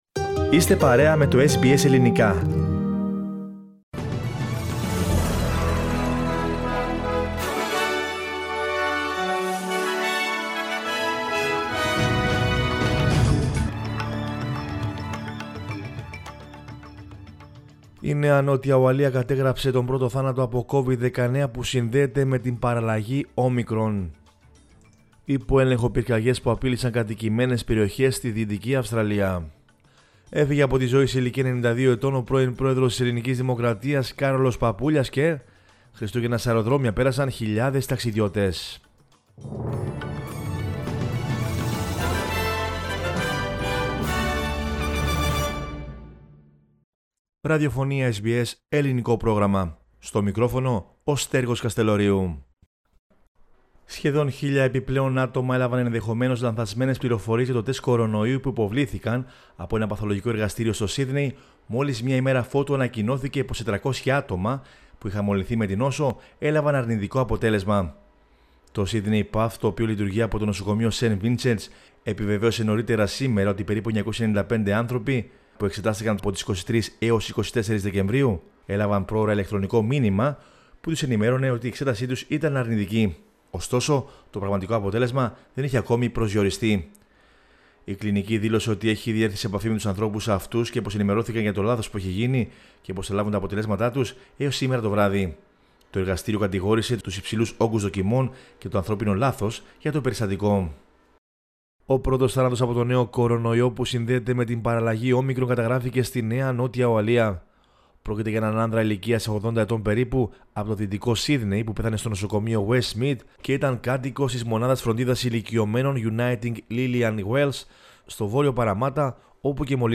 News in Greek from Australia, Greece, Cyprus and the world is the news bulletin of Monday 27 December 2021.